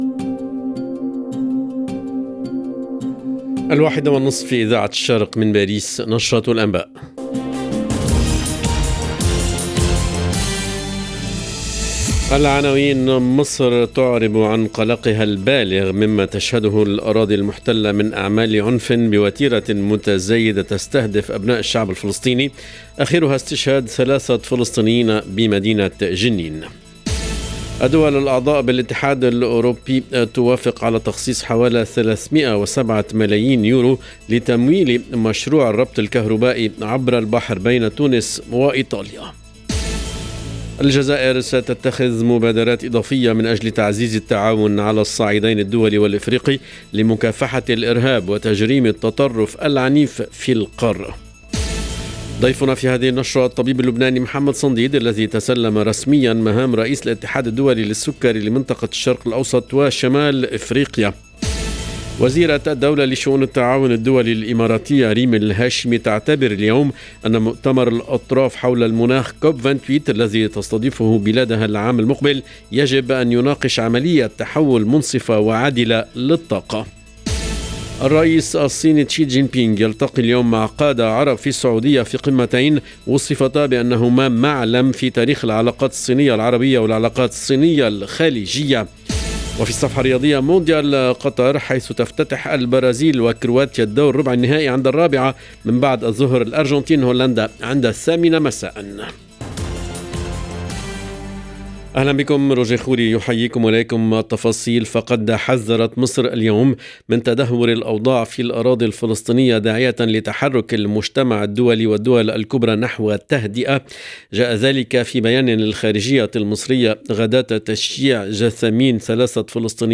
LE JOURNAL EN LANGUE ARABE DE LA MI-JOURNEE DU 9/12/22